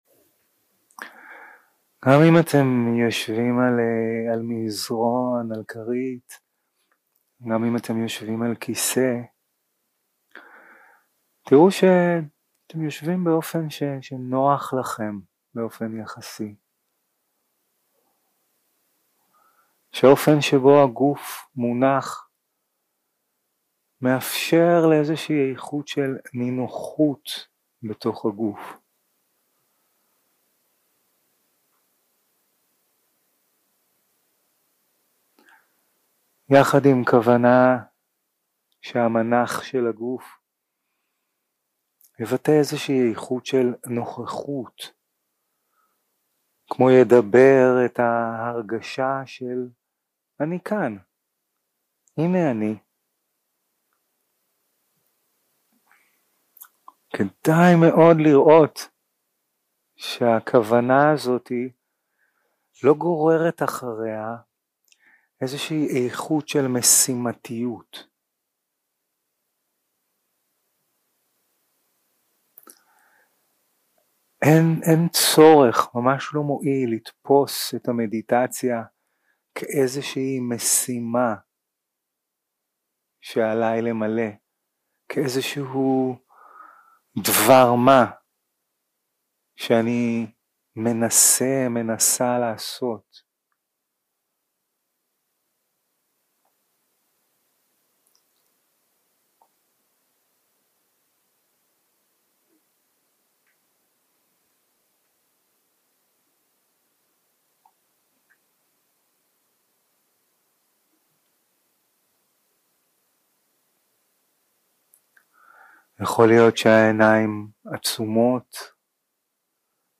יום 1 – הקלטה 1 – ערב – מדיטציה מונחית
Dharma type: Guided meditation